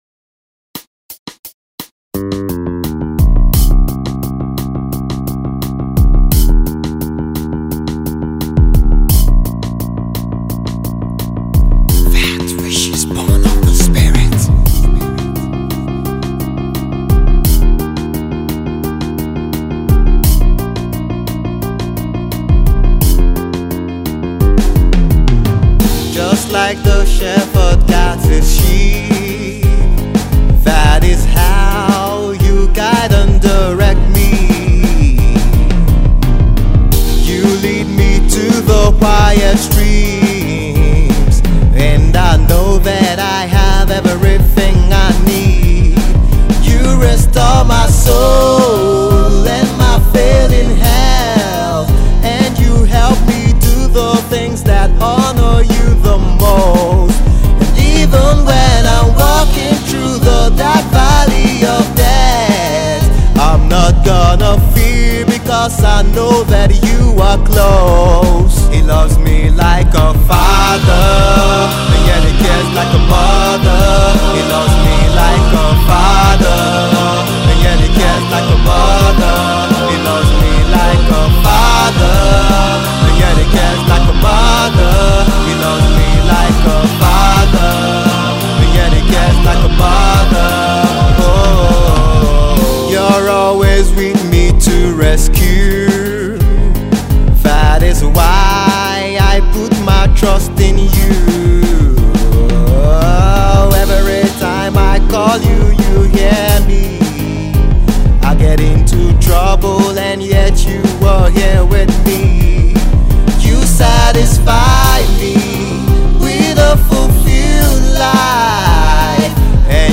All the vocals (lead and back up) were done by me. I also did the instrumentals by myself.